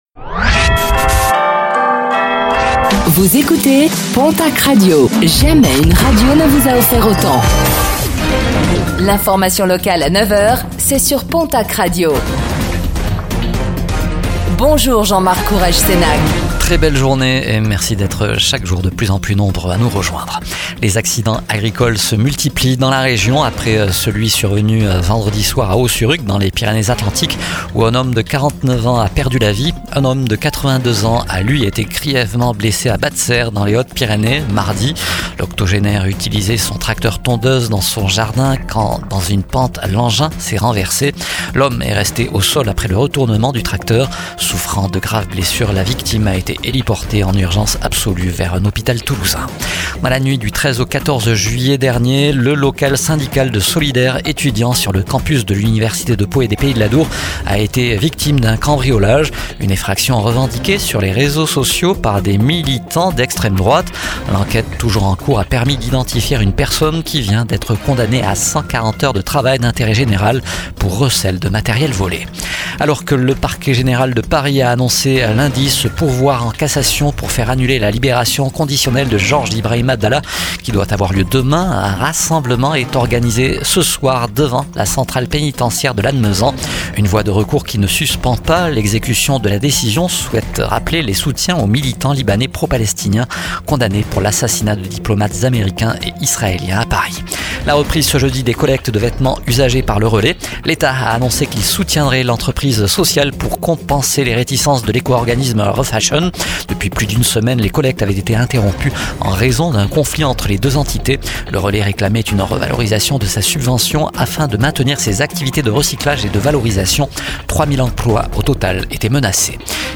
09:05 Écouter le podcast Télécharger le podcast Réécoutez le flash d'information locale de ce jeudi 24 juillet 2025